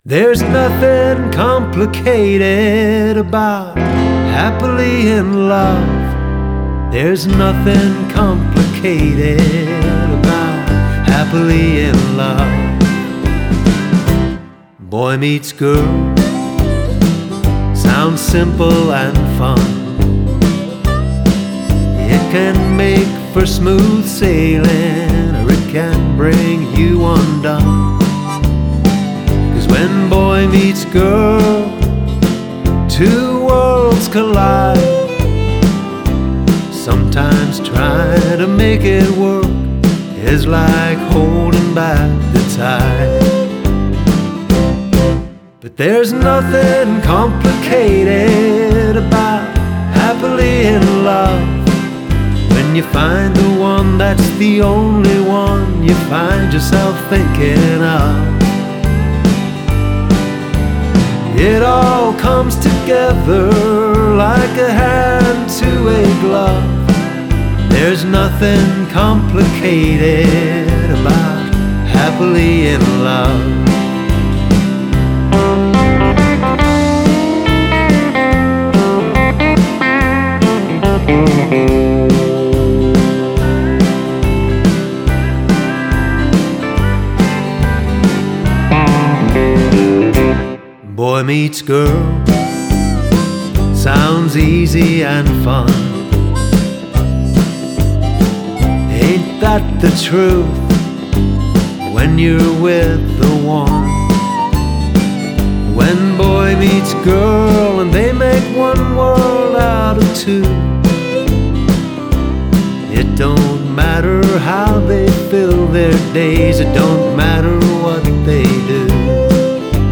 feel-good tune